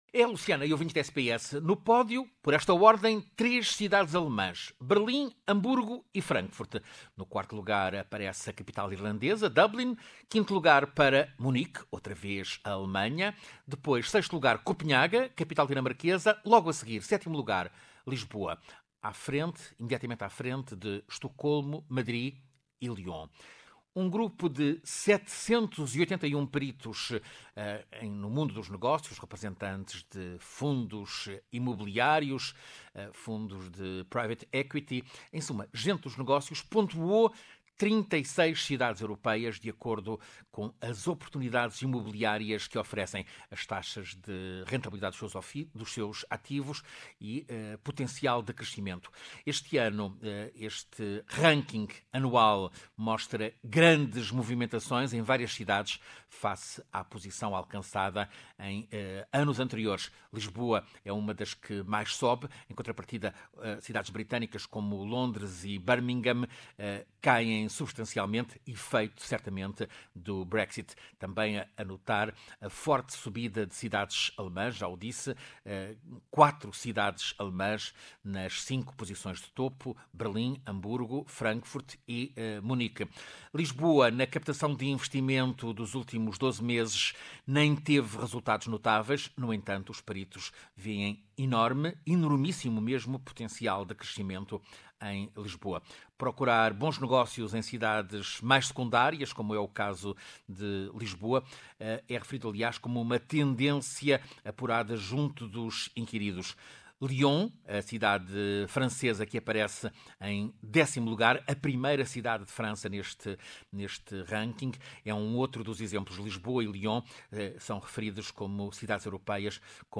Quatro cidades alemãs nos cinco primeiros lugares. Reportagem